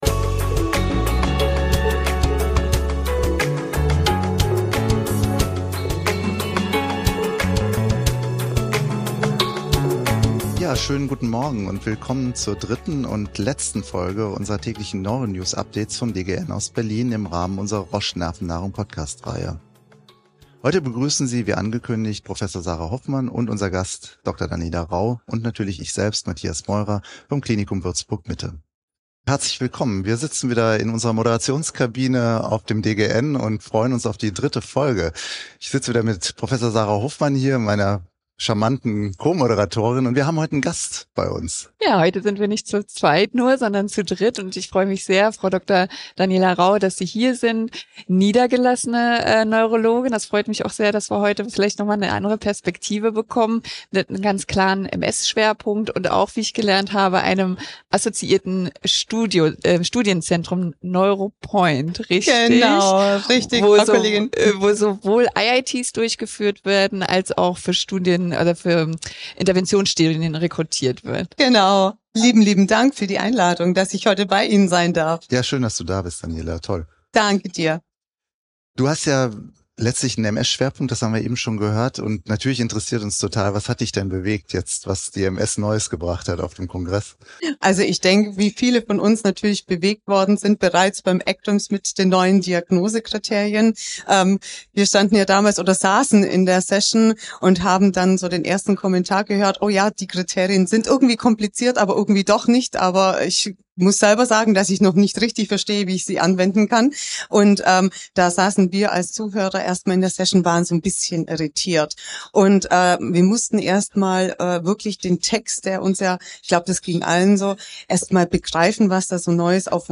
Beschreibung vor 1 Jahr Die Nervennahrung Neuro News des dritten Tages live vom DGN Kongress in Berlin.